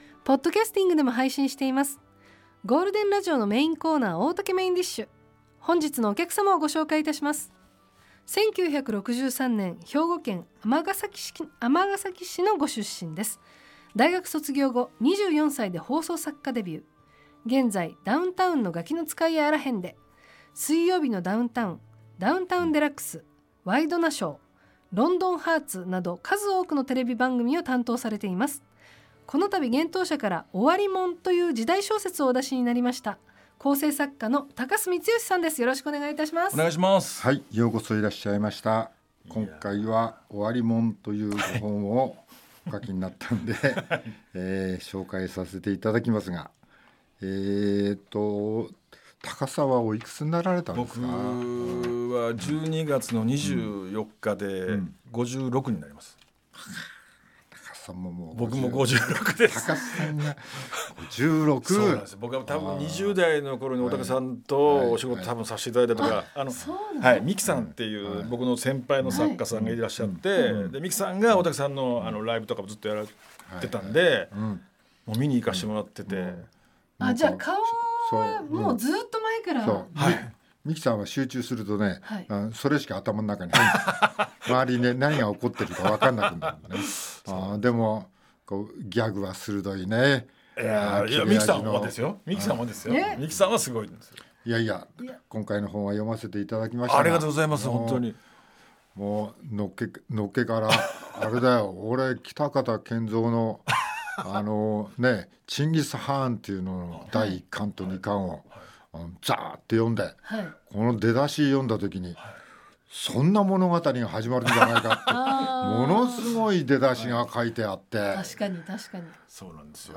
番組のメインを飾るゲストが登場！ 大竹まこと＆各パートナーがお客様のトークを料理します。